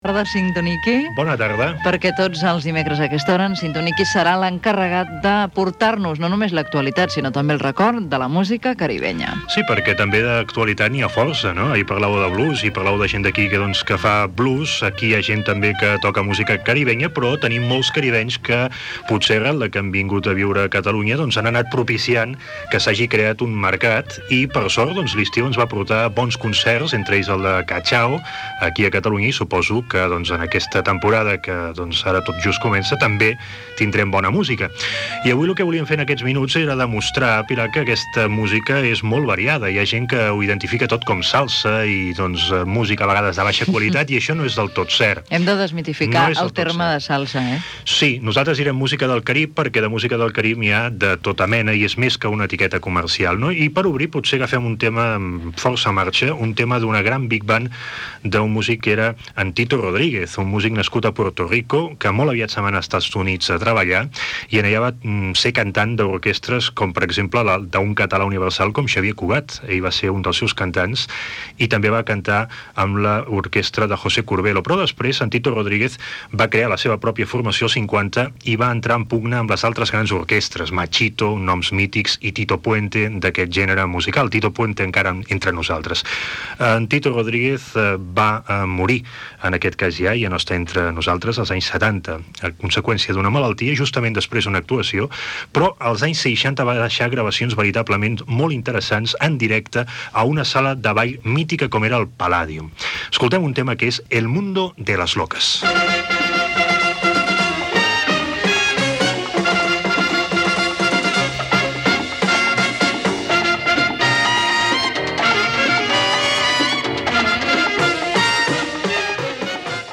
Presentador/a
FM